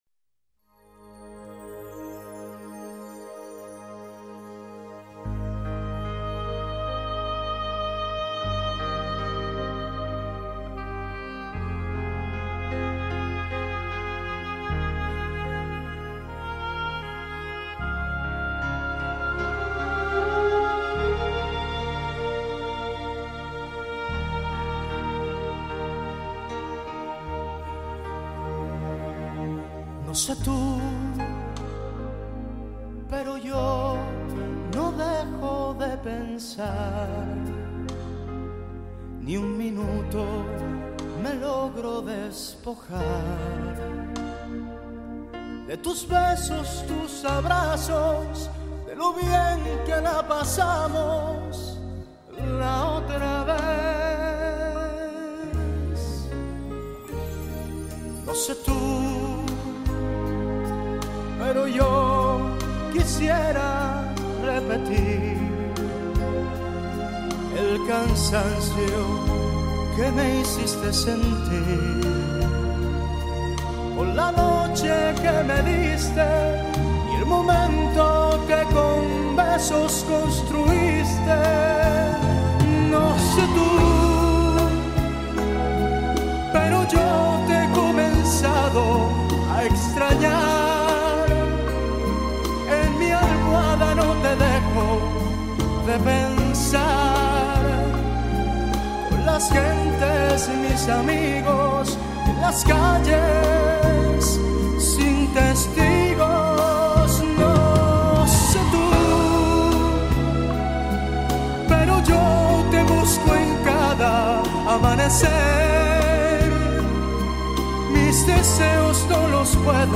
Carpeta: Lentos en español mp3